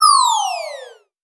ihob/Assets/Extensions/CartoonGamesSoundEffects/Falling_v1/Falling_v5_wav.wav at master
Falling_v5_wav.wav